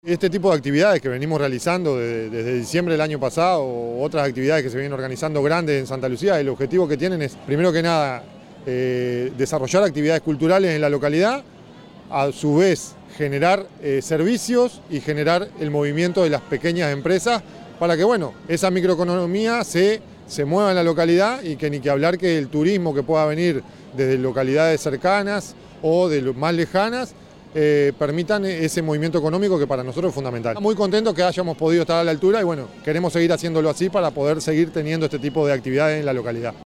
Por su parte, el Alcalde del Municipio de Santa Lucía, Leonardo Mollo, afirmó que este tipo de actividades tienen como objetivo realizar actividades culturales en la ciudad y, al mismo tiempo, generar servicios y movimiento de las pequeñas empresas para que la microeconomía se mueva en la localidad.
alcalde_leonardo_mollo.mp3